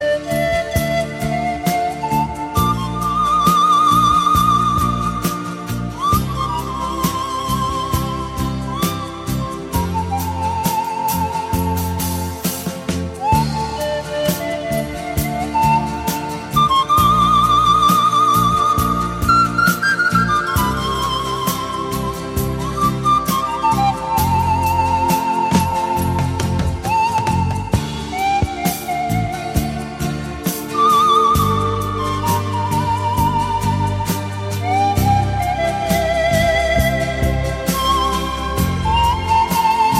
без слов
инструментальные , ретро
на флейте